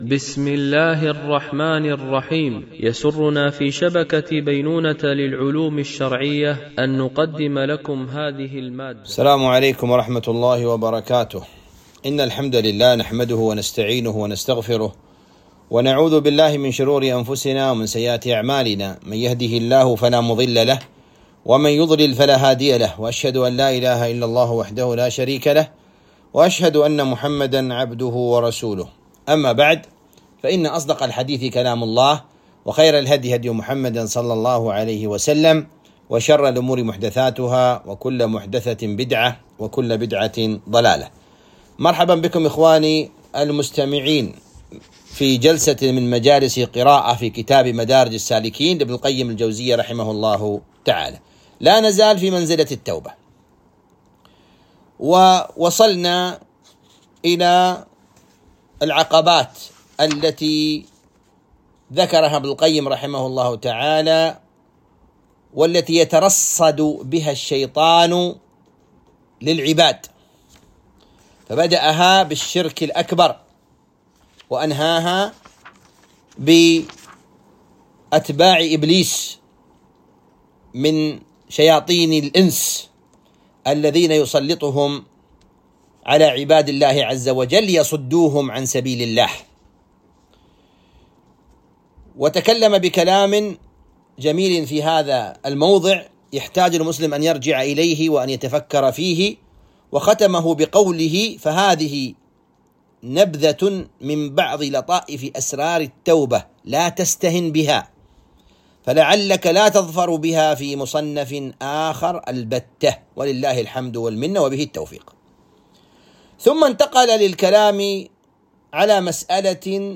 قراءة من كتاب مدارج السالكين - الدرس 29